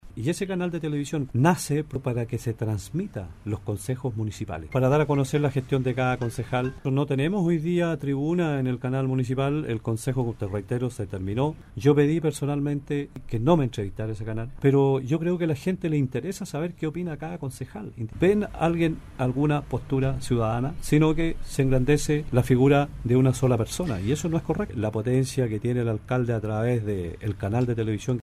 Dura es la crítica que extendió en el programa Haciendo Ciudad de Radio Sago, el concejal PPD por Osorno, Juan Carlos Velásquez al alcalde Jaime Bertín.